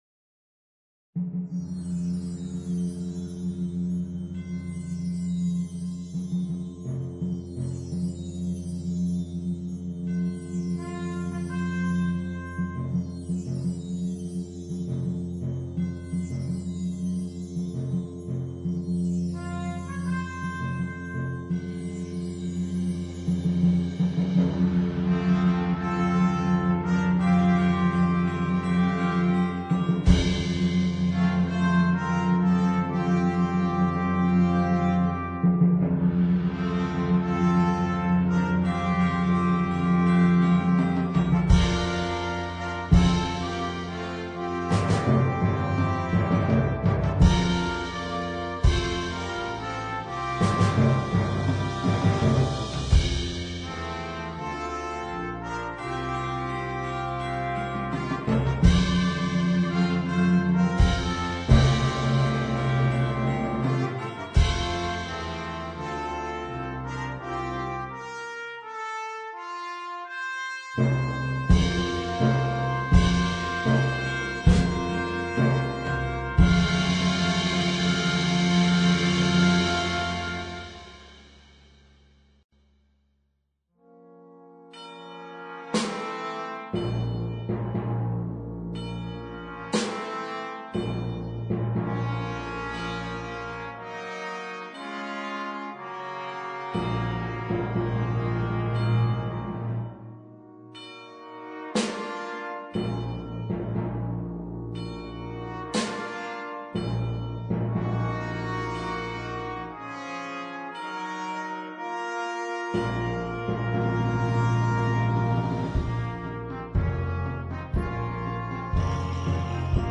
für flexibles Ensemble
Besetzung: Blasorchester